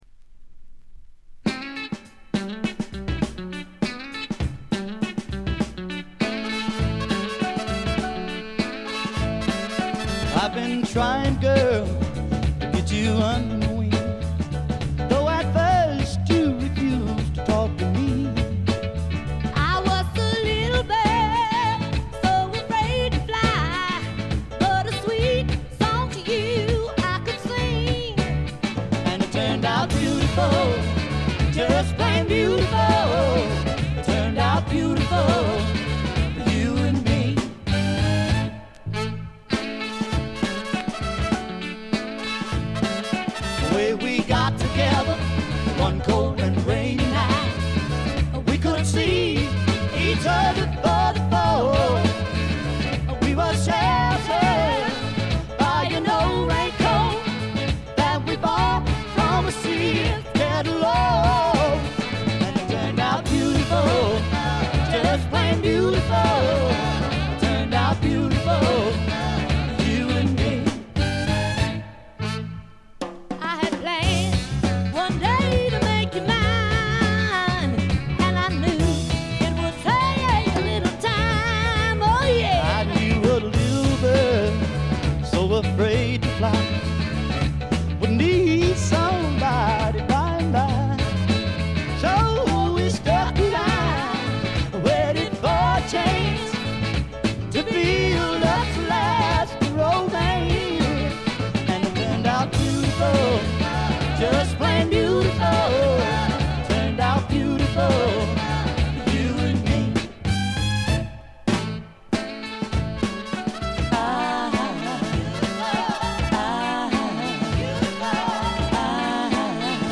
ほとんどノイズ感無し。
南部ソウル完璧な一枚。
試聴曲は現品からの取り込み音源です。